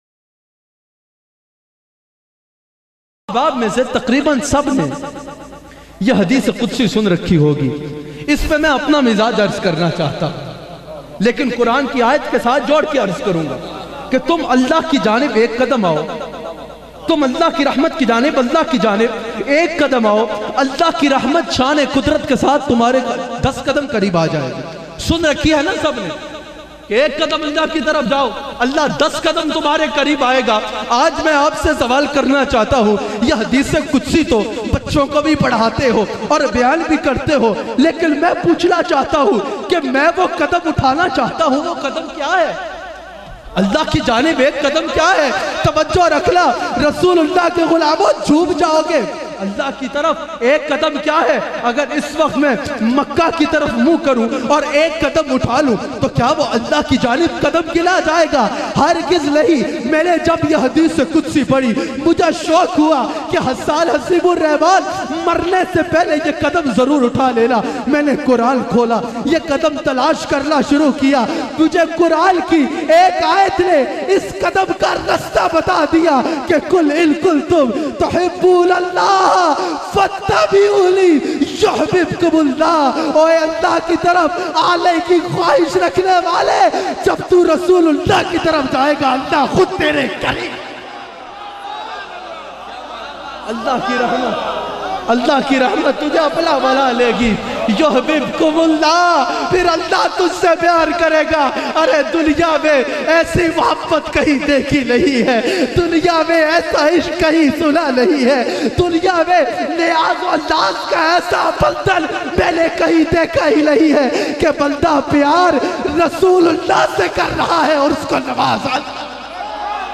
Cryfull bayan